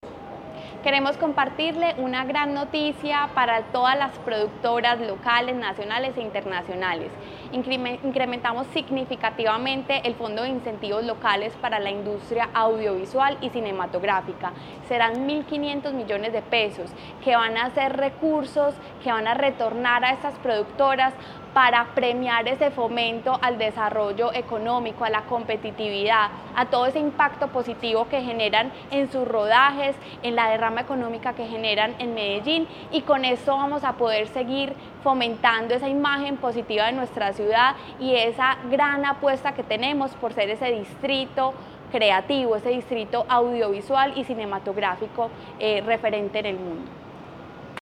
Palabras de María Fernanda Galeano, secretaria de Desarrollo Económico